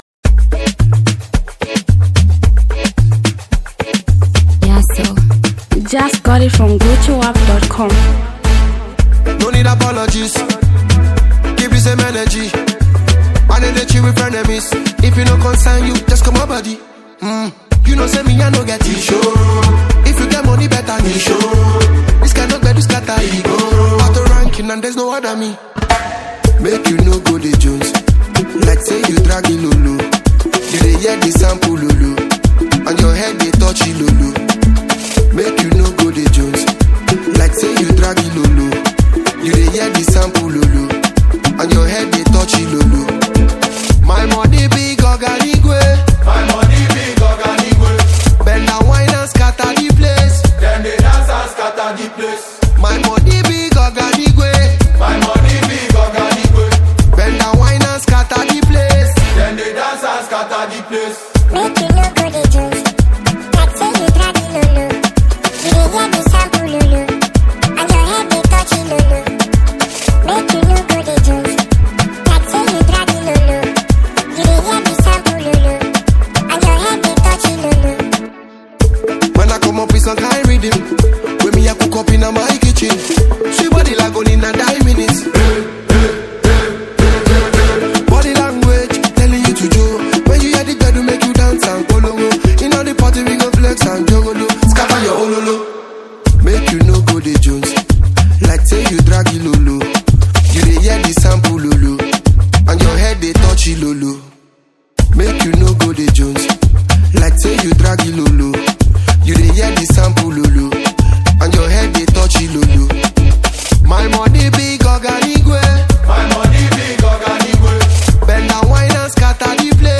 reggae Nigerian super star.